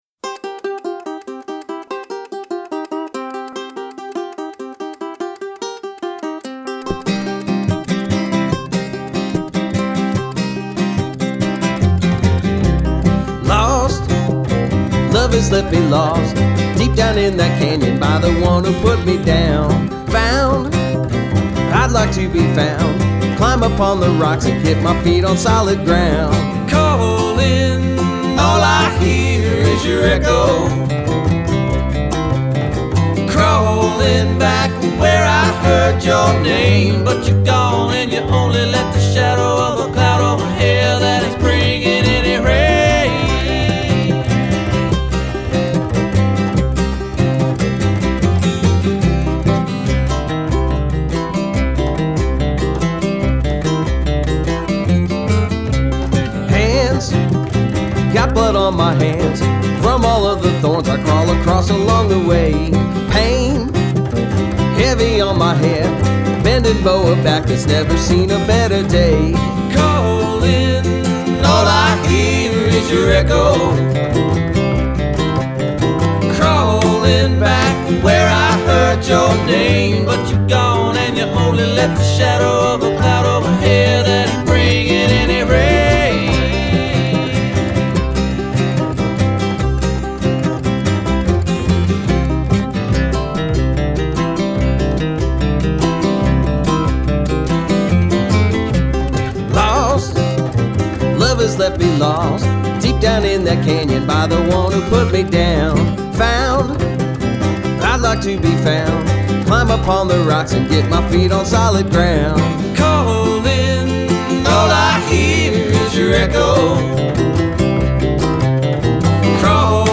Genre: AAA, Folk, Alt-Country, Bluegrass
guitar, banjo, bass, vox
upright bass, vox
mandolin, uke, guitar, vox